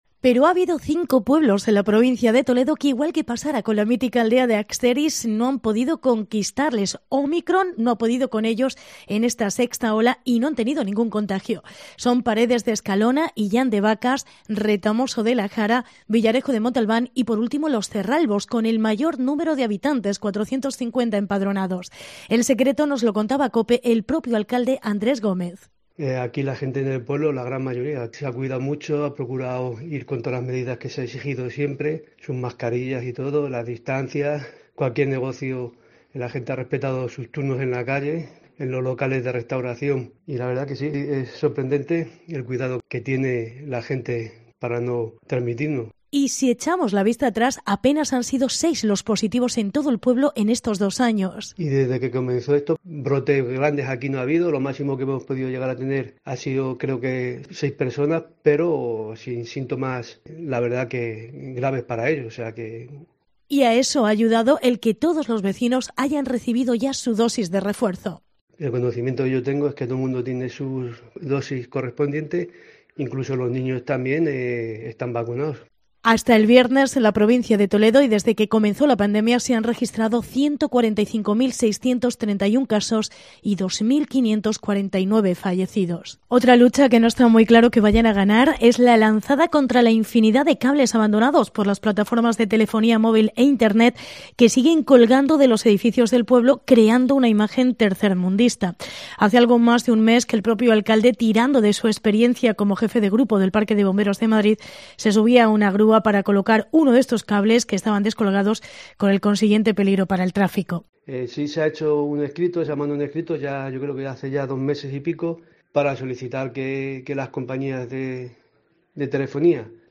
Entrevista Andrés Gómez alcalde de los Cerralbos